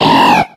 BARBOACH.ogg